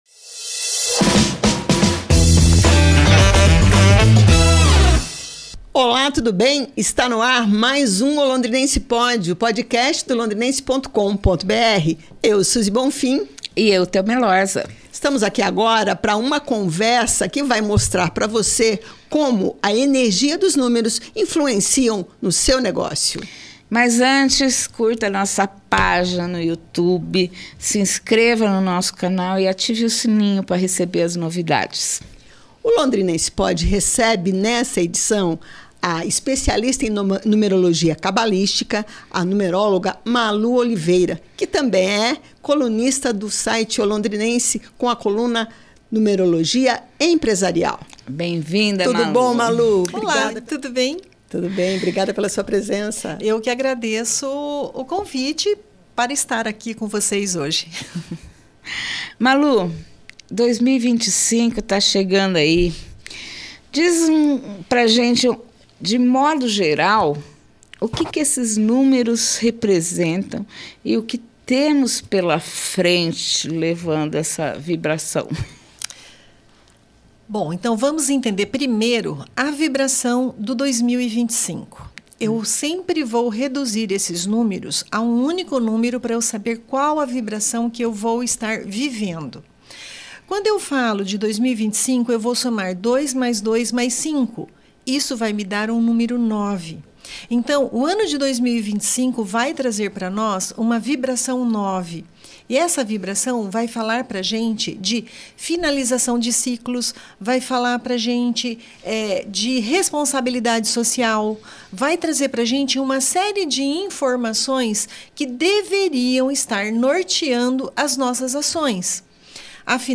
Pois saiba como a Numerologia Empresarial pode ajudar. Esta entrevista